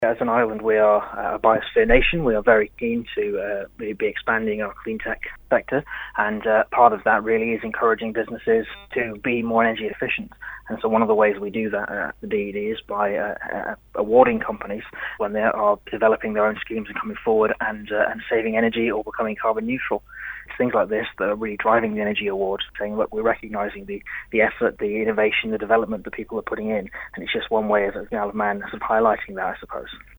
Department member with responsibility for Clean Tech and Energy Lawrie Hooper MHK says he's delighted with the efforts made by Island firms: